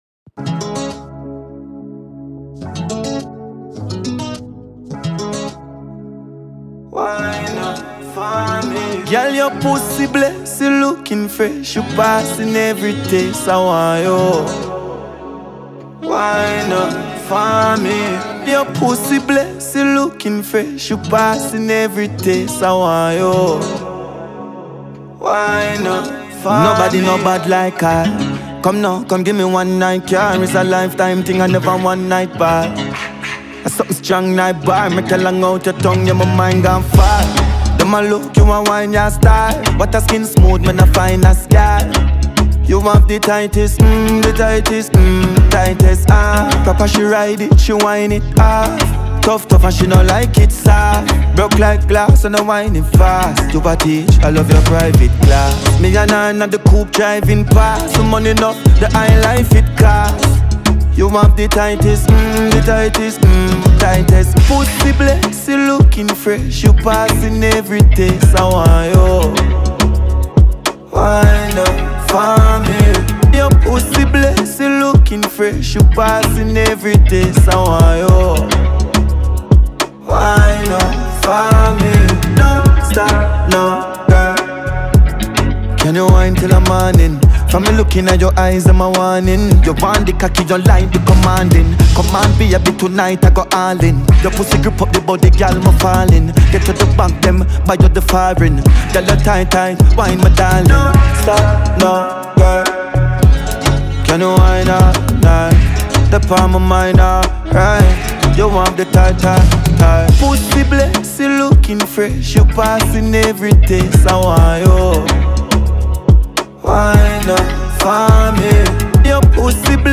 With its catchy hook and dance-driven beat
• Genre: Dancehall